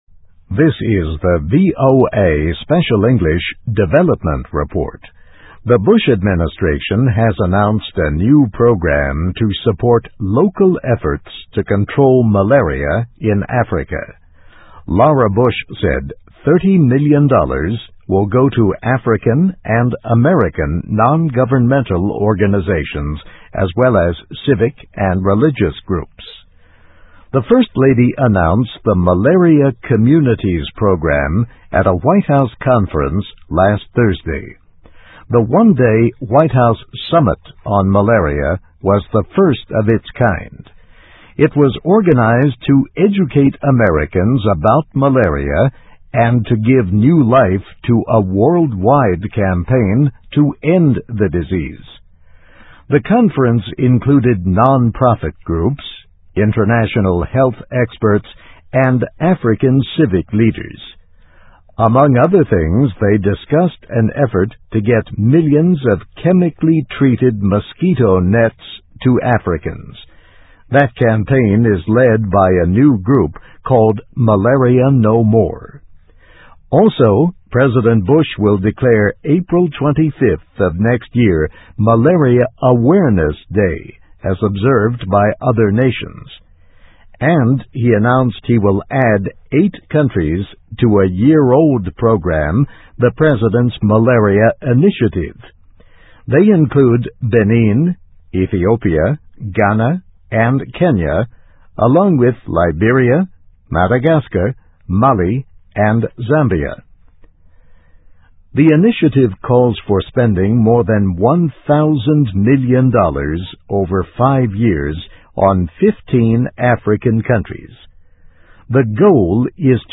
Voice of America Special English